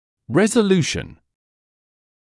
[ˌrezə’luːʃn][ˌрэзэ’луːшн]разрешение, устранение (напр. воспаления); рассасывание (напр. опухоли); растворение